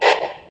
step.ogg.mp3